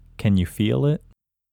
LOCATE OUT English Male 29